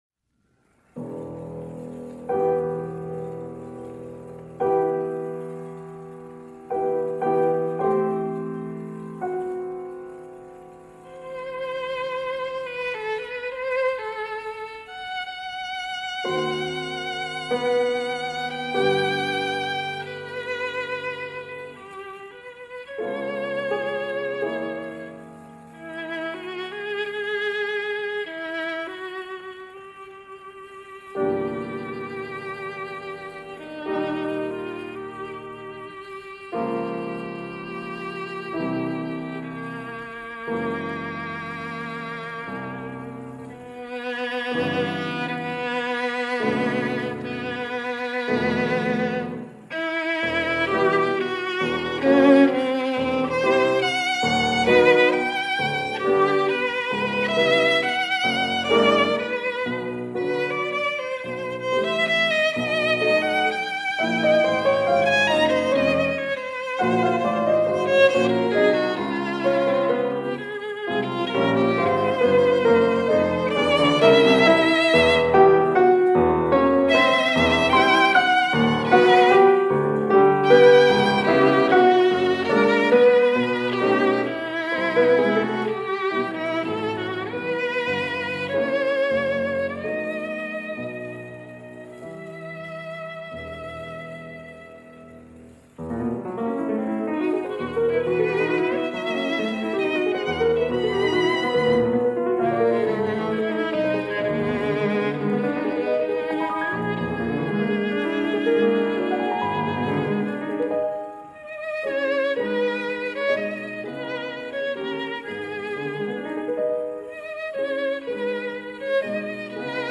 violin
piano – CBC Transcription Service circa. 1956
This weekend it’s his seldom-performed chamber music.
in this circa 1956 CBC Radio session